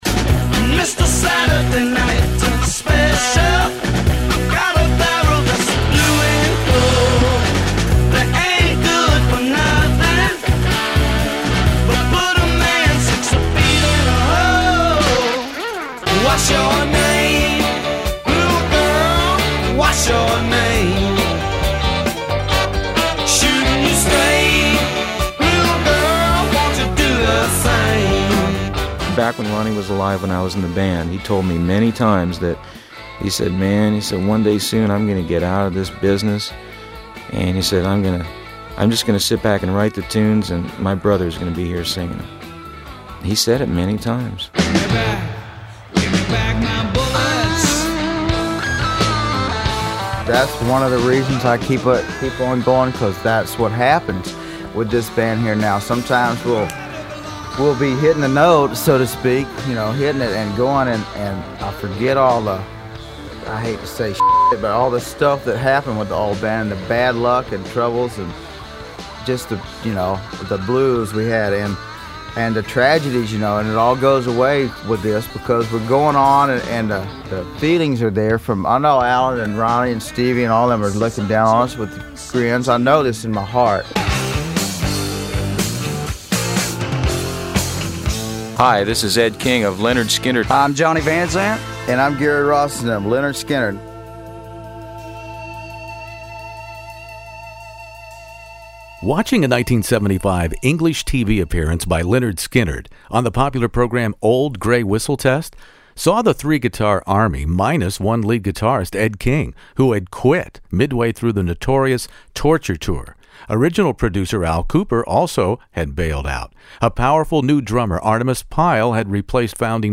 Lynyrd Skynyrd "Nuthin' Fancy" interview Gary Rossington In the Studio